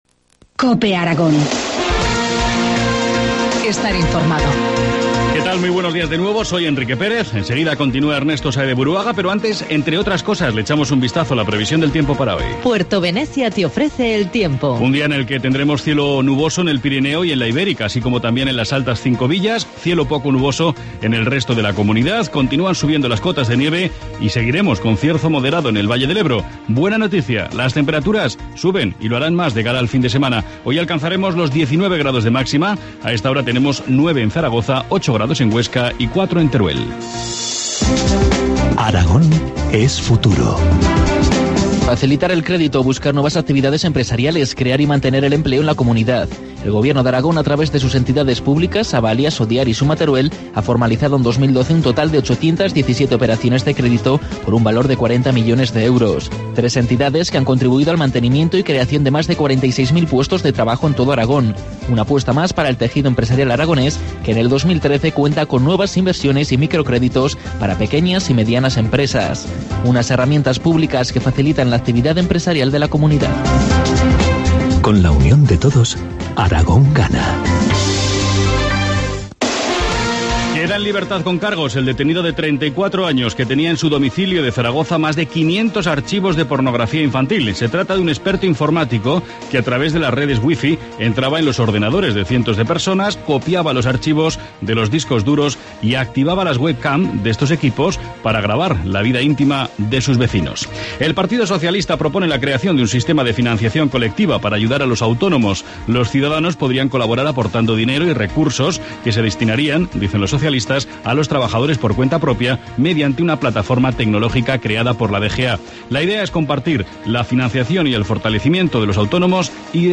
Informativo matinal, viernes 3 de mayo, 8.25 horas